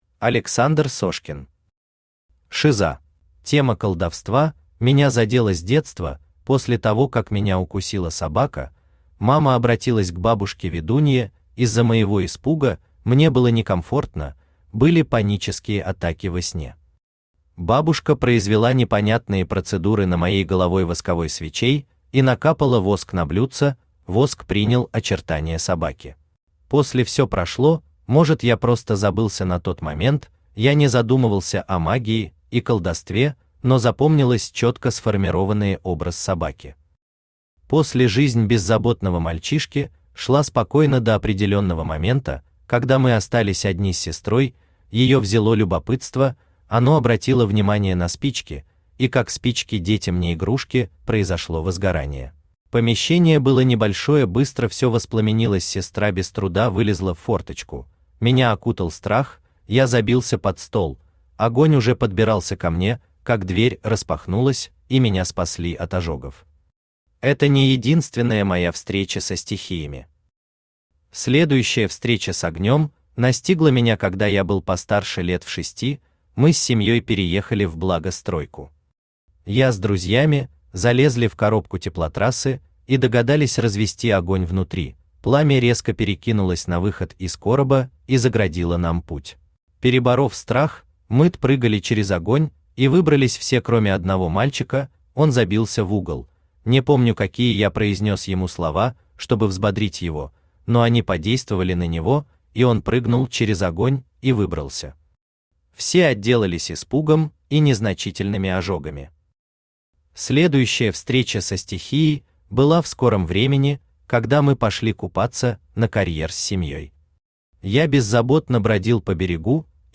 Аудиокнига Шиза | Библиотека аудиокниг
Aудиокнига Шиза Автор Александр Павлович Сошкин Читает аудиокнигу Авточтец ЛитРес.